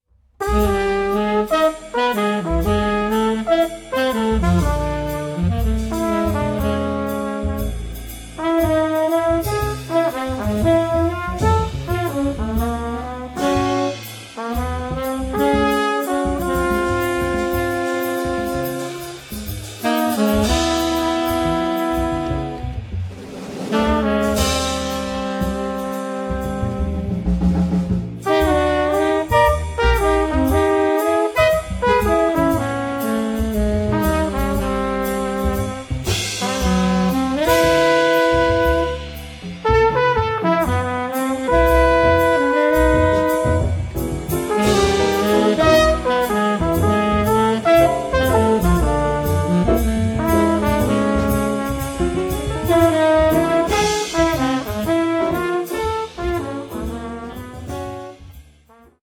hard bop
trumpet
alto sax
tenor sax
piano
bass
drums